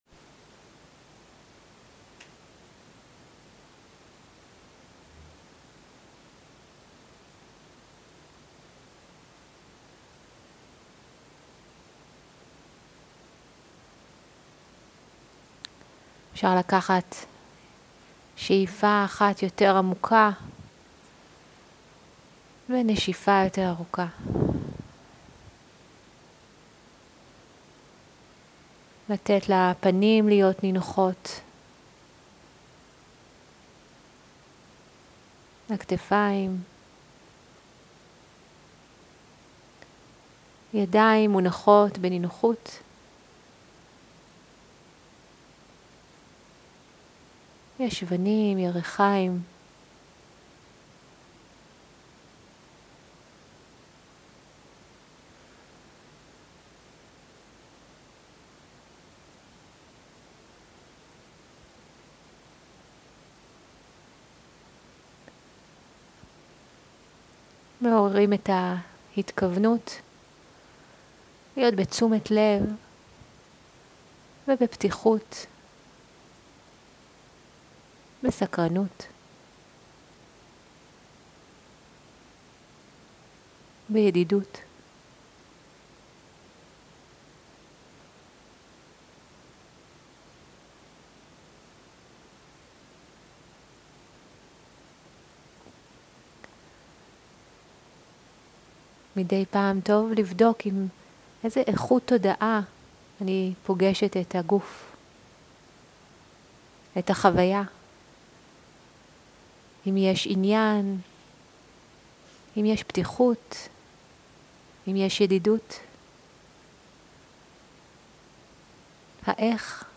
מדיטציה מונחית ודנא
Guided meditation